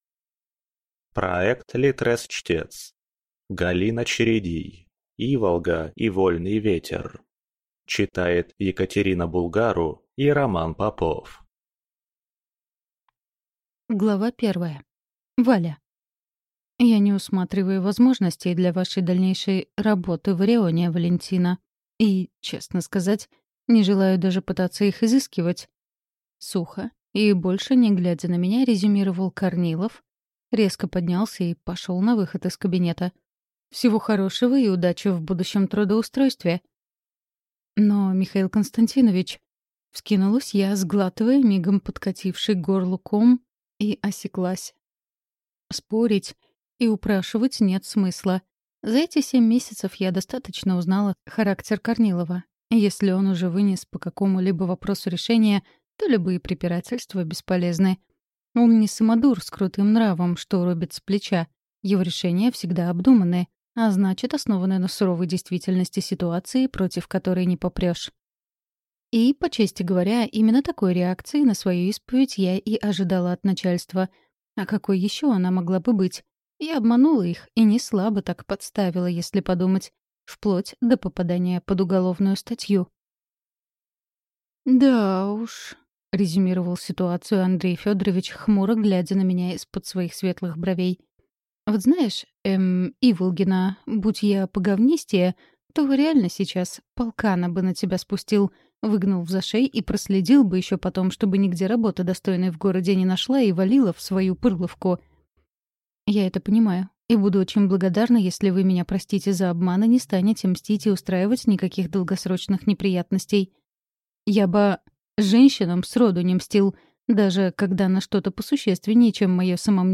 Шидонай-Сирота. Часть 1. Добро пожаловать на Бару! (слушать аудиокнигу бесплатно) - автор Влада Ольховская